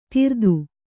♪ tirdu